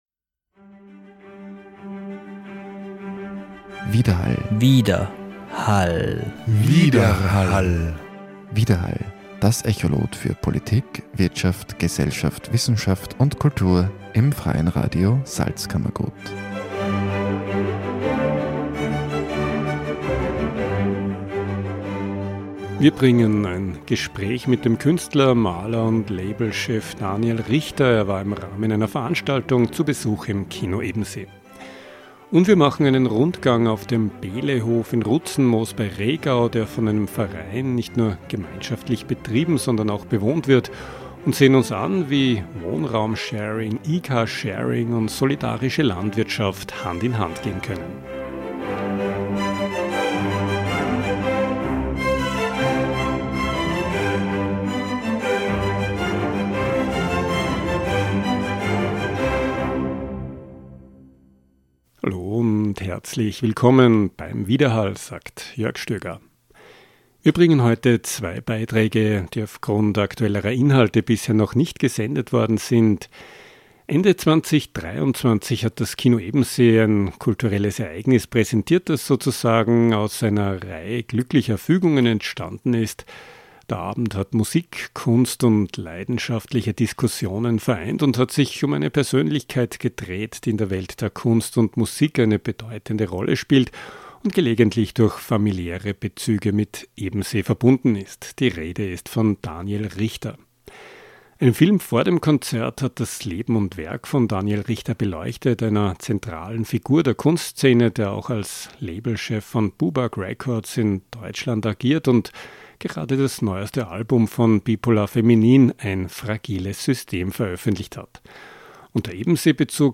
Interessanterweise stammen die Mitglieder der Band Bipolar Feminin ebenfalls aus Ebensee. Das Gespräch fand im Vorfeld der Veranstaltung statt.